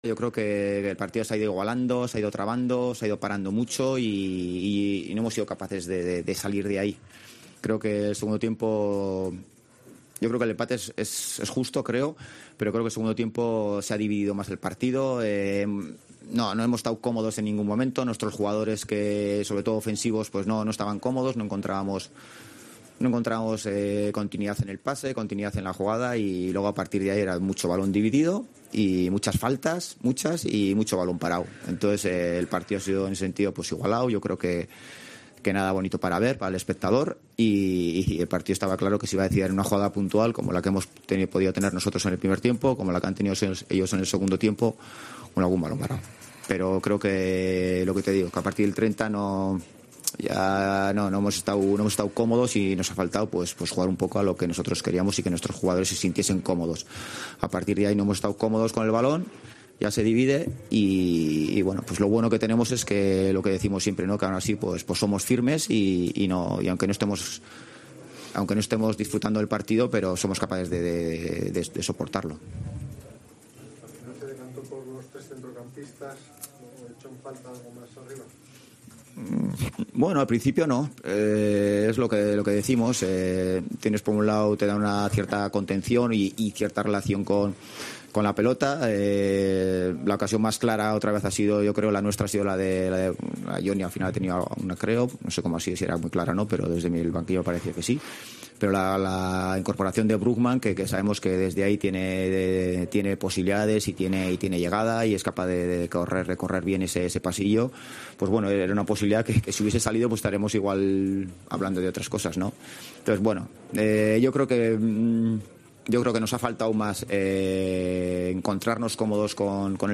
Rueda de prensa Ziganda (post Fuenlabrada)